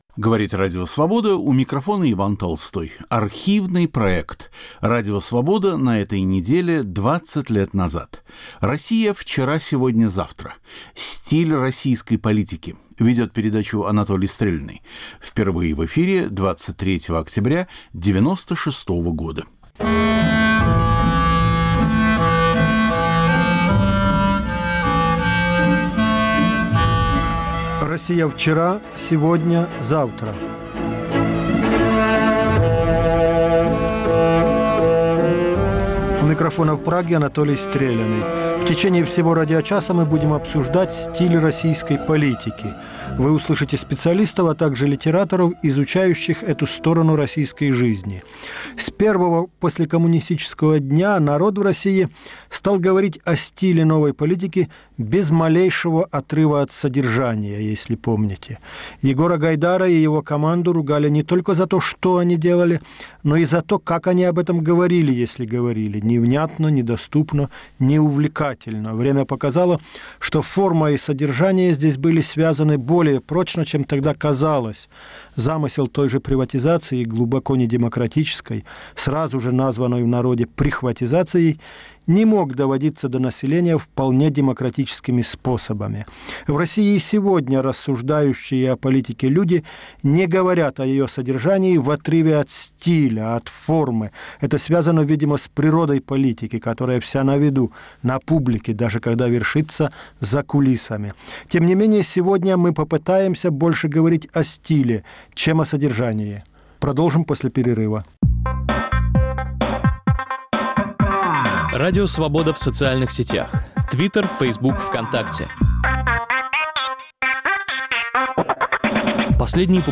Ведет программу Анатолий Стреляный. Впервые в эфире – 23 октября 1996.